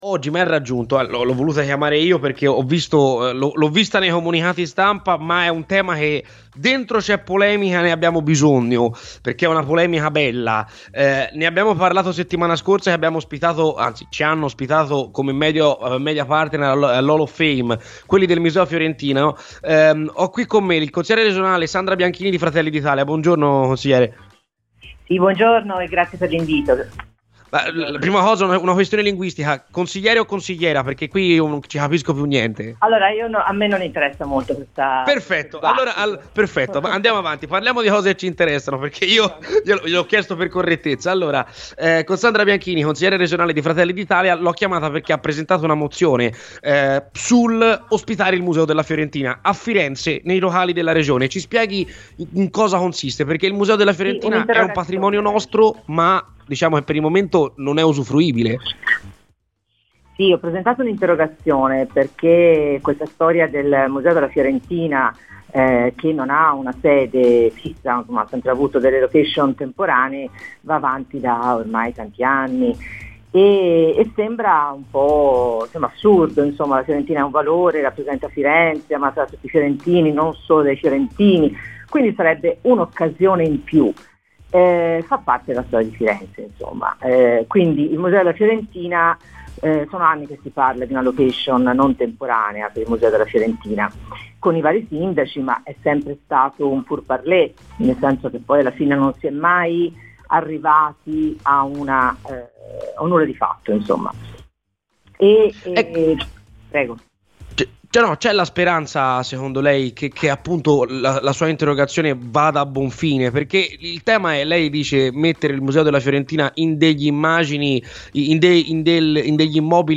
Sandra Bianchini, consigliere regionale di Fratelli d'Italia, è intervenuta oggi a Radio Firenzeviola, durante "C'è Polemica", lanciando un appello: "Ho presentato un'interrogazione in consiglio regionale perché la storia del Museo Fiorentina che non ha una sede va avanti da molti anni e mi pare assurdo perché ha un valore per tutti i fiorentini e non.